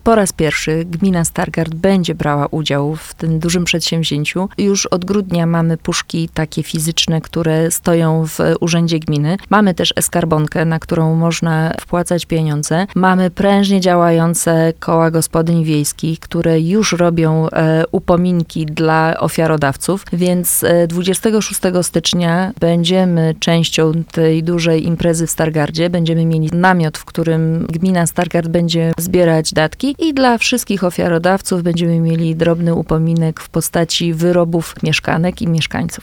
Mówiła Patrycja Gross, wójt Gminy Stargard.